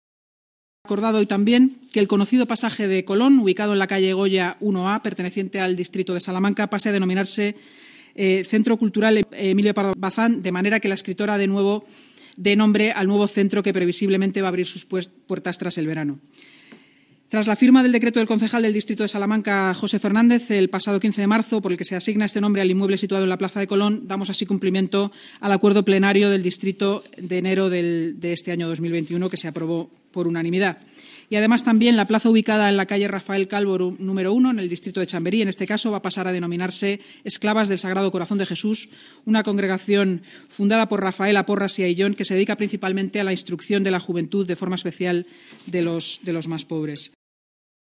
Nueva ventana:Inmaculada Sanz, portavoz municipal